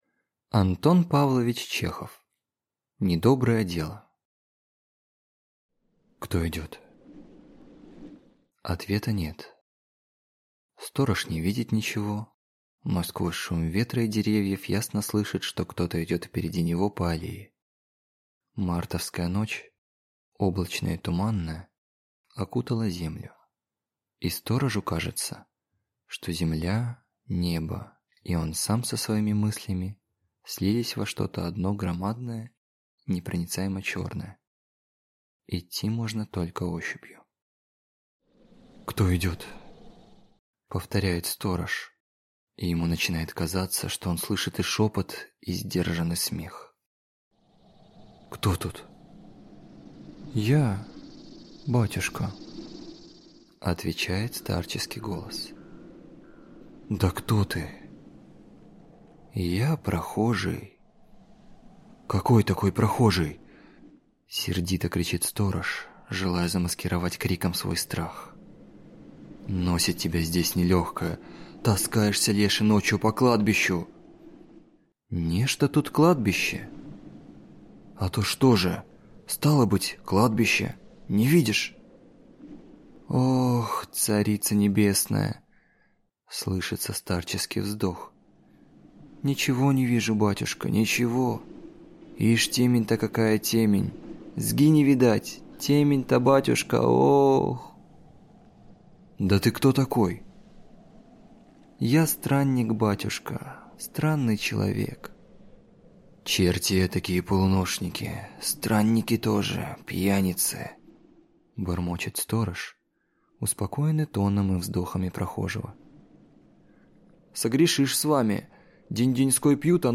Аудиокнига Недоброе дело | Библиотека аудиокниг